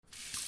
水槽里的水
描述：Sennheiser ME40。放大H6。地点：NSCAD大学。哈利法克斯，NS。加拿大
标签： 水槽 排水
声道立体声